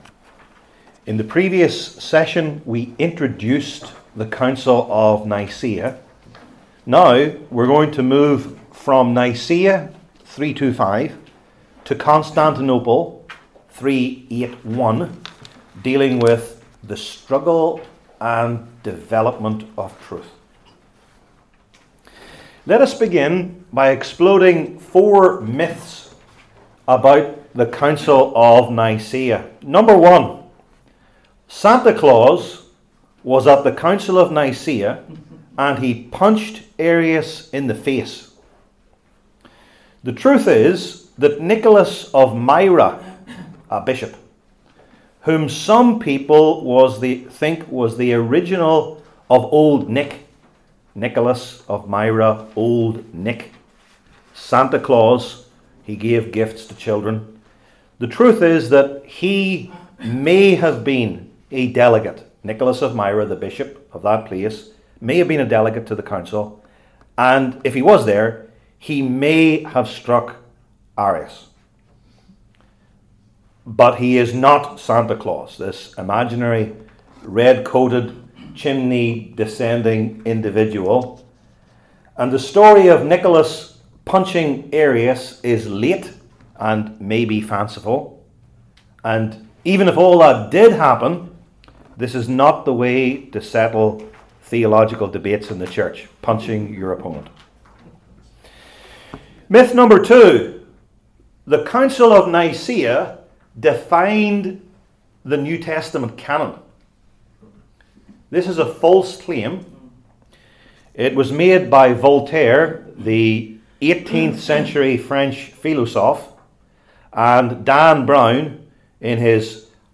Lectures/Debates/Interviews